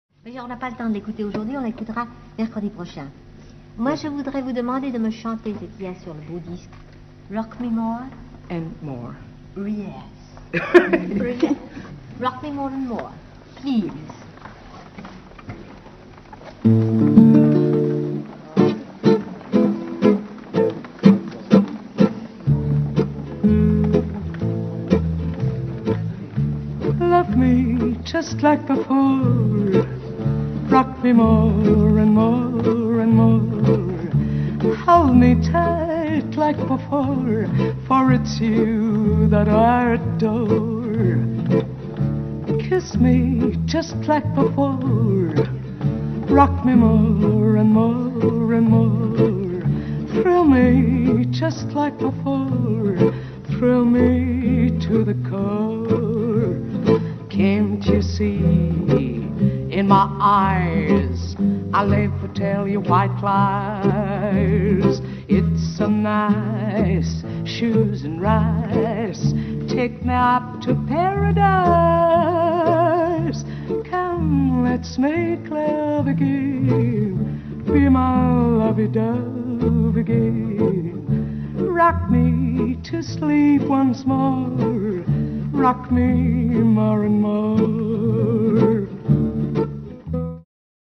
仏蘭西で、1958年ごろからギター１本のブルーズ歌手